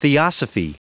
Prononciation du mot theosophy en anglais (fichier audio)
Prononciation du mot : theosophy